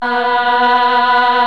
M CHOIR  1.1.wav